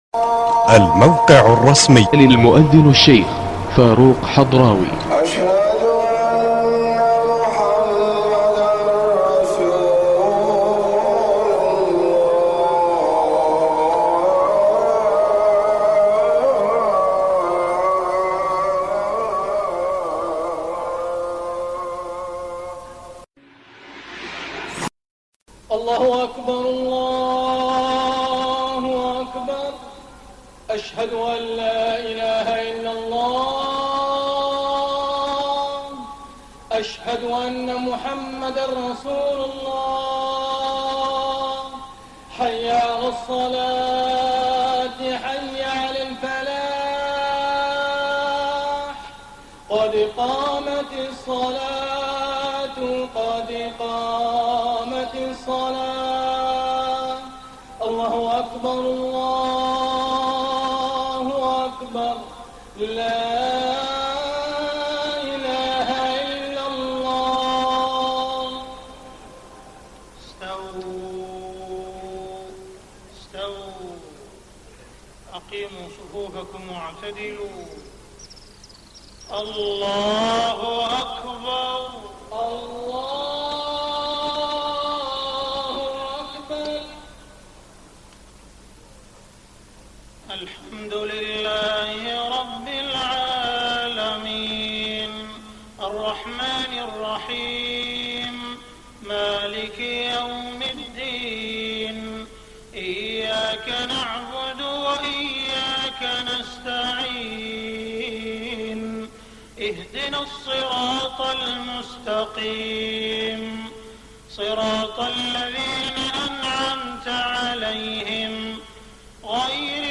صلاة المغرب21 رمضان 1423هـ من سورة إبراهيم > 1423 🕋 > الفروض - تلاوات الحرمين